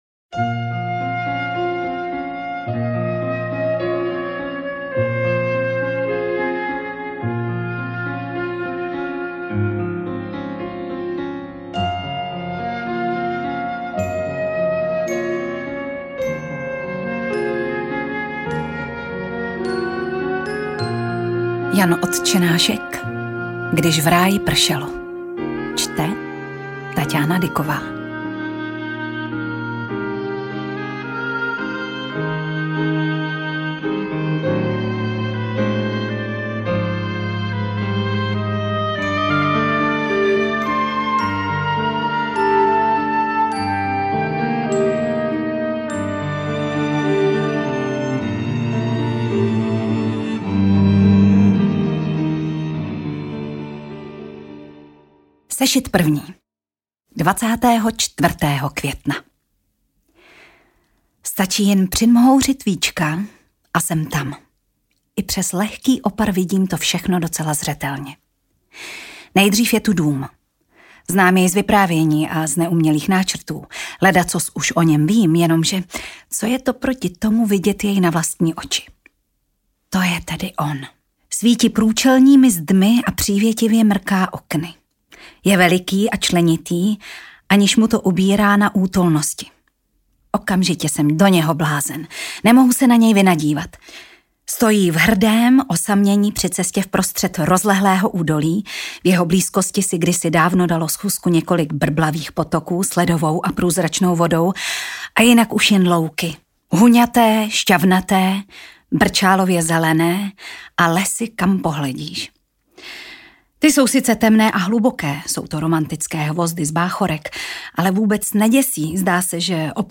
AudioKniha ke stažení, 39 x mp3, délka 7 hod., velikost 382,0 MB, česky